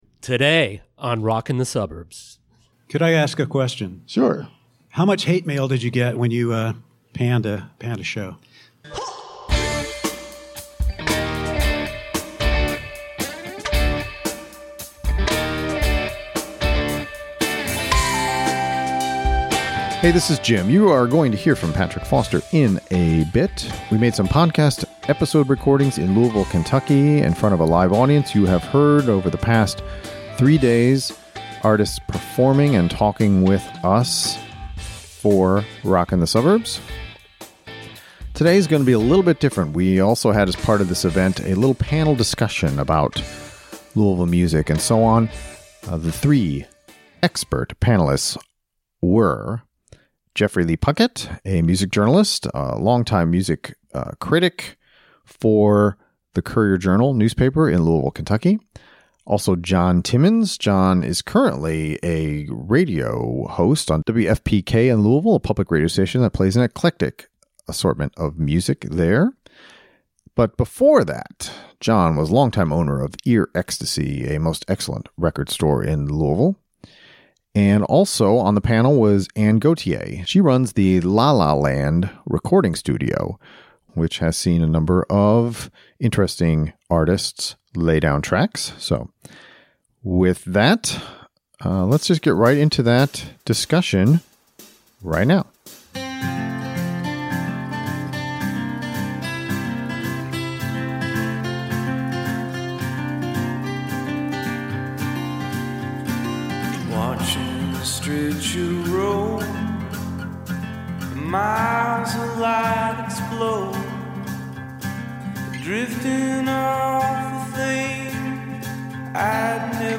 Recorded live in front of an audience at the Whirling Tiger in Louisville, part of Suburbs Fest South.